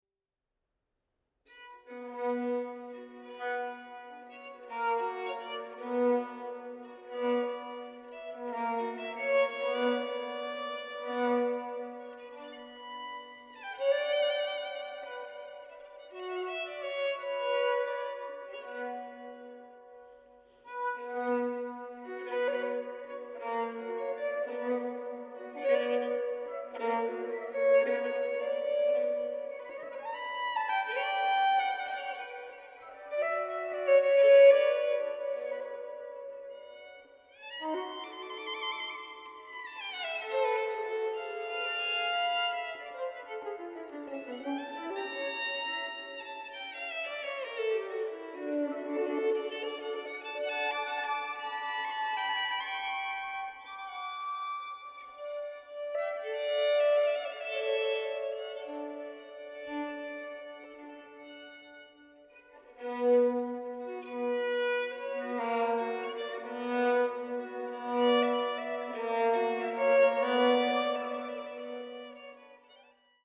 • Genres: Baroque, Classical, Strings